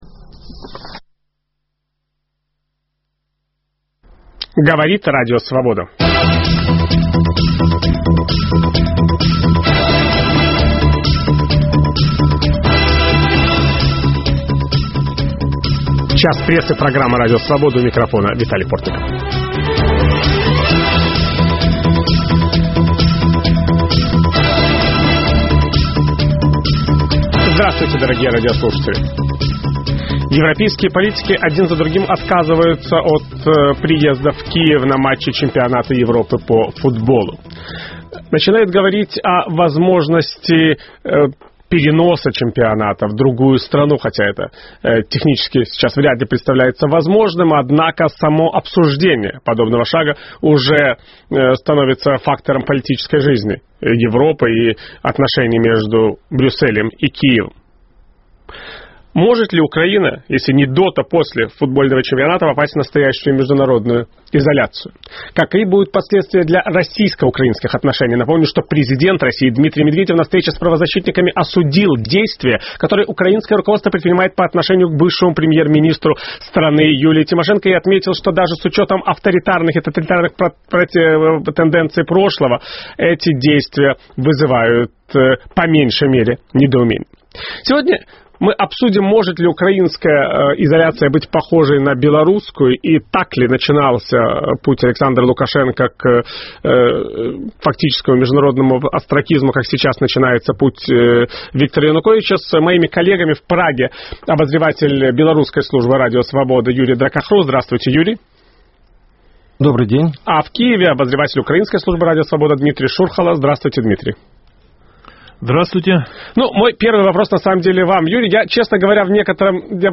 Утром в газете, а с часу дня в прямом эфире - обсуждение самых заметных публикации российской и зарубежной печати. Их авторы и герои - вместе со слушателями.